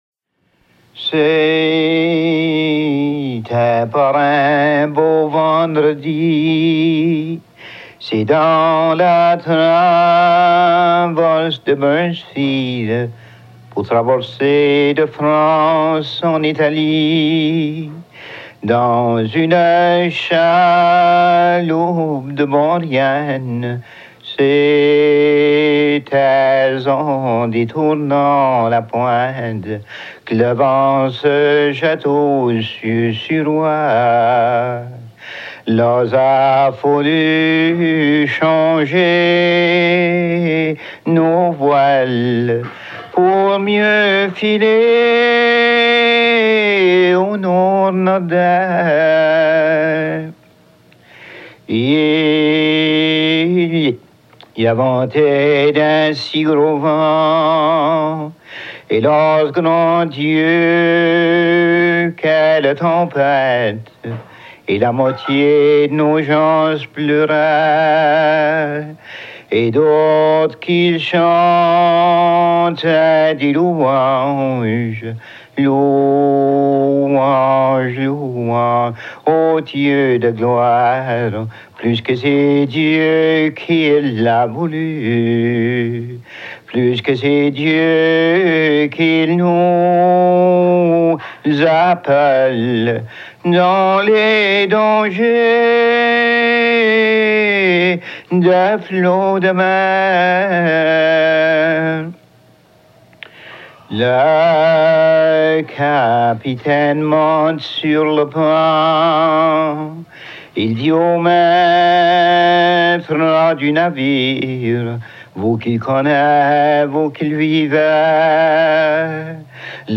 Enrgistré à Tracadie, co. Gloucester, Nouveau-Brunswick
Genre strophique
Pièce musicale éditée